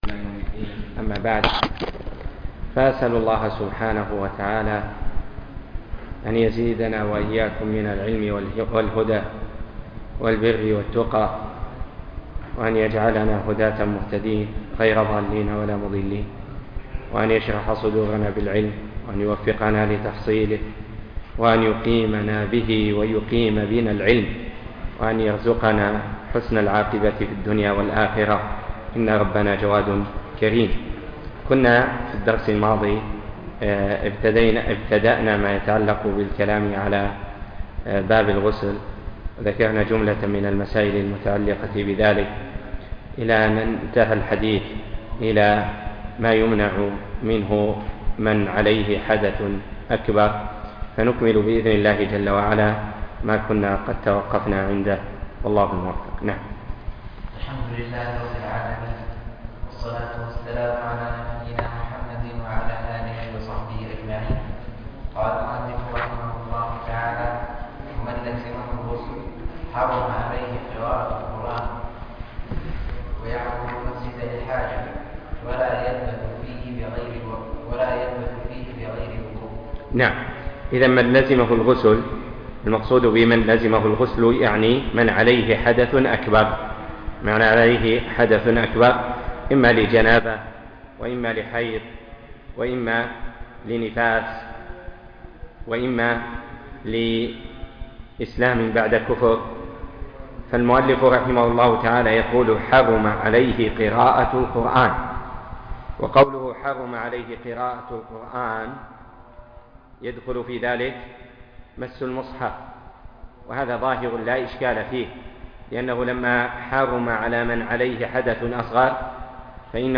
زاد المستقنع - باب الغسل - باب التيمم - الدرس (12)